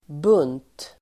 Uttal: [bun:t]